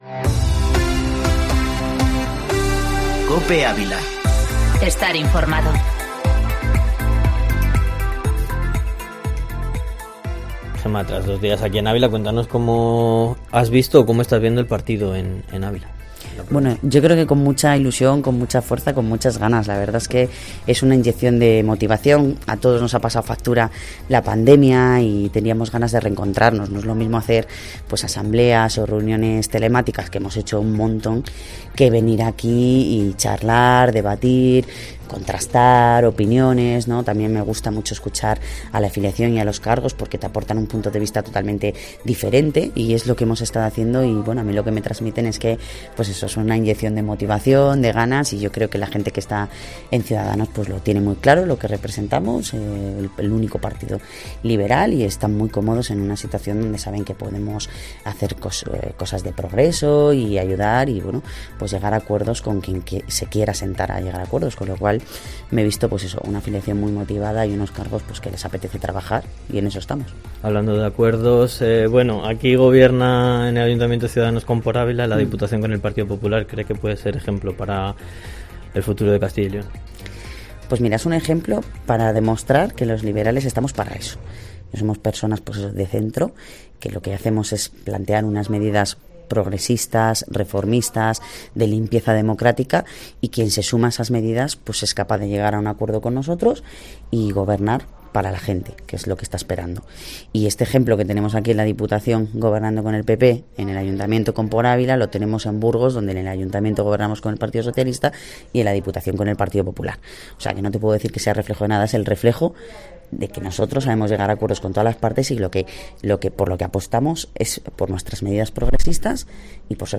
Entrevista en COPE Ávila con la coordinadora de Ciudadanos en Castilla y León, Gemma Villarroel